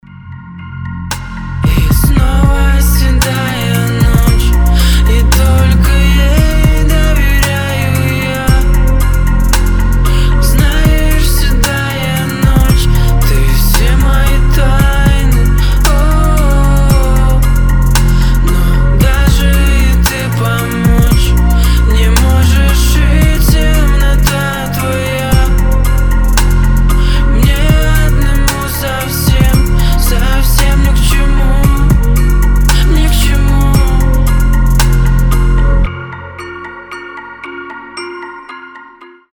• Качество: 320, Stereo
грустные
Trap
Cover
тиканье часов